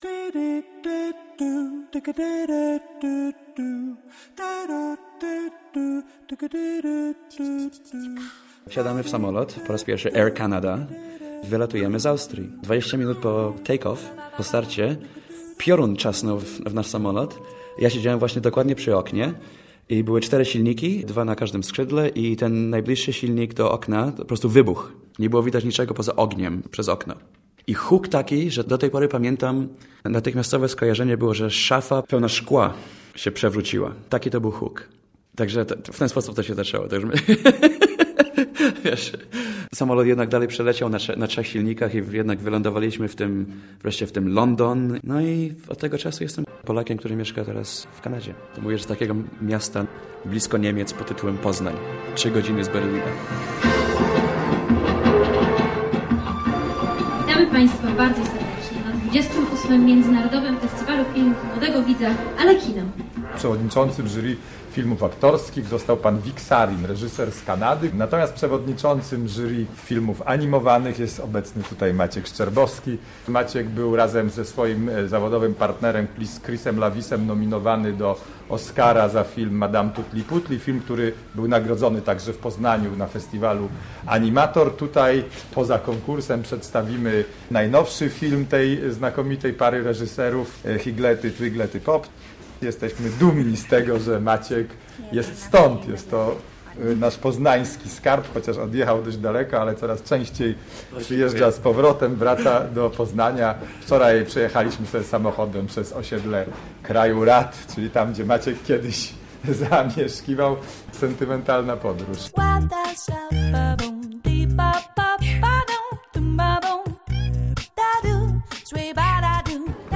Życie to musi być coś więcej - reportaż